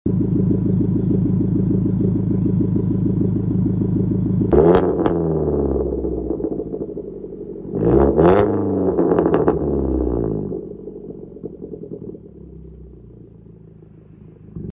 what do yalls exhaust sound sound effects free download